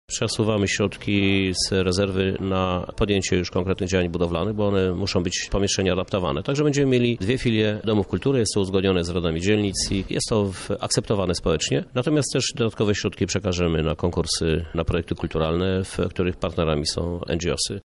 O szczegółach mówi prezydent Lublina Krzysztof Żuk.